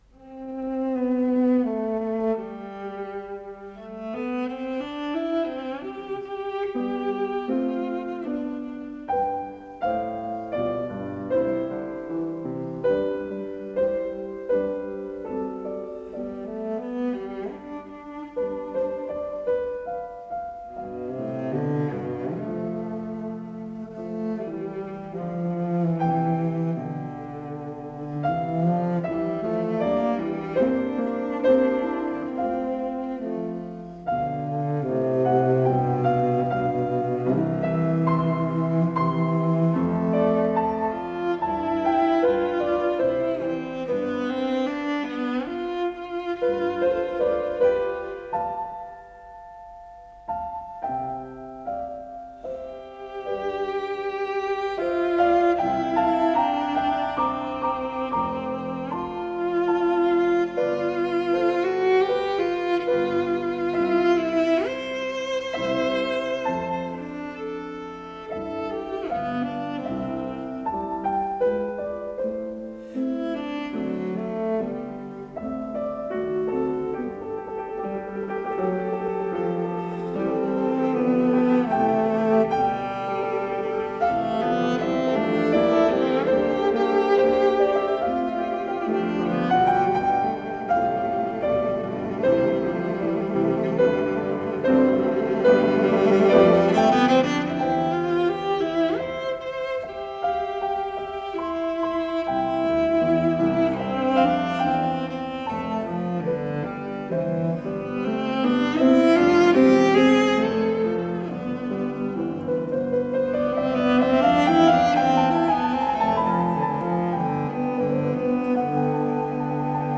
с профессиональной магнитной ленты
Анданте, виваче
виолончель
ВариантДубль моно